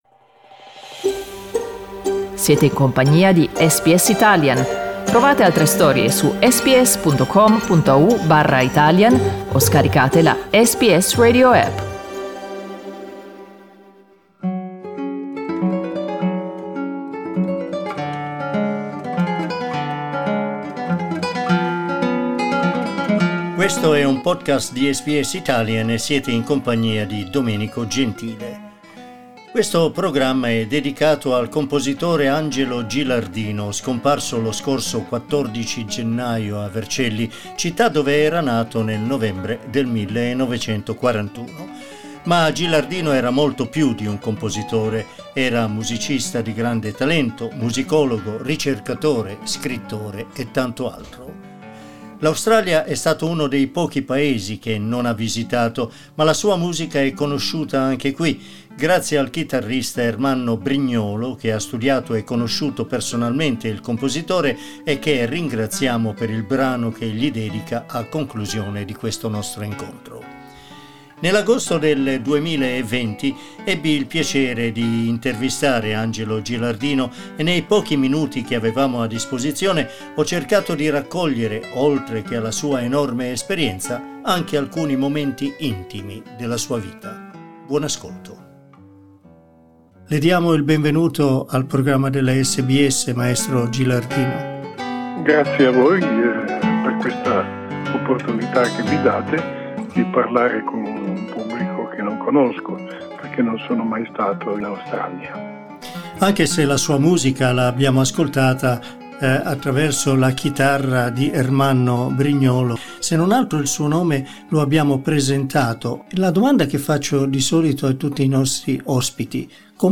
Lo scorso 14 gennaio a Vercelli, città dove era nato nel novembre del 1941, è scomparso il compositore Angelo Gilardino. Lo ricordiamo proponendovi una intervista che rilasciò a SBS Italian nell'agosto del 2020.